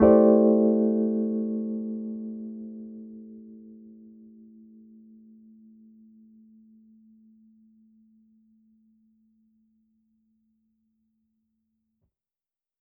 Index of /musicradar/jazz-keys-samples/Chord Hits/Electric Piano 3
JK_ElPiano3_Chord-Am11.wav